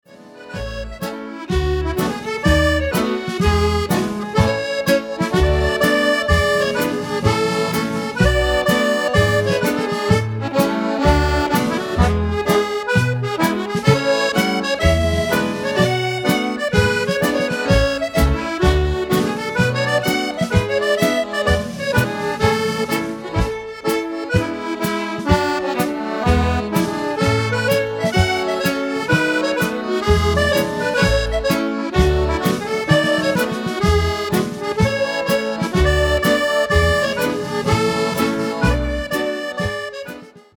8 x 32 Strathspey
accordion
fiddle
drums